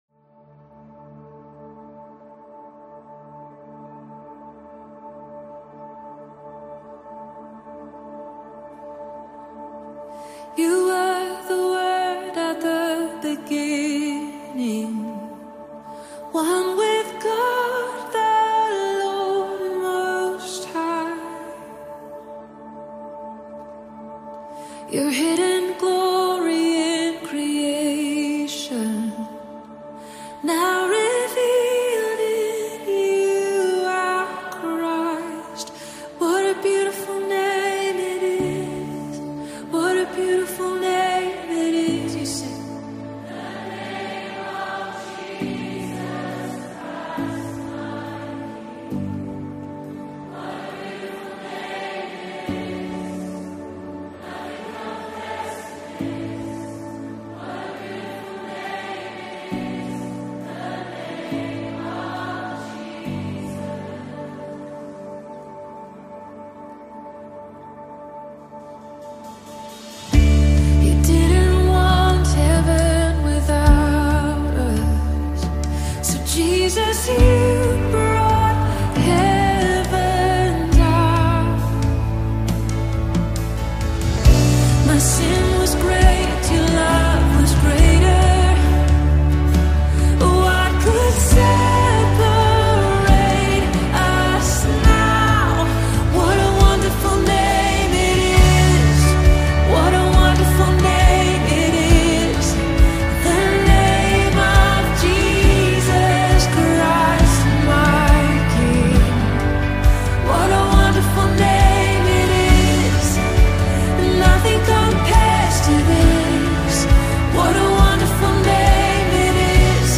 a majestic worship song
Gospel Songs